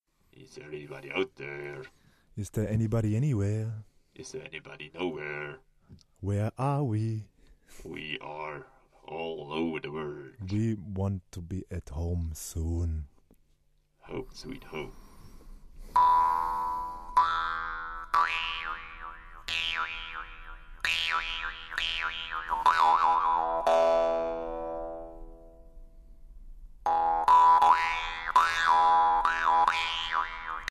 Didgeridoomusik